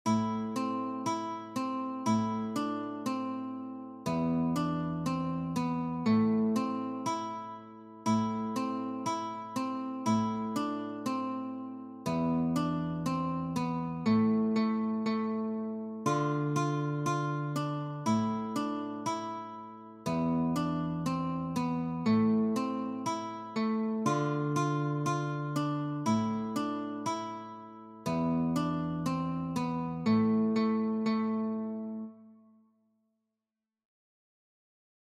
Sololiteratur
Gitarre (1)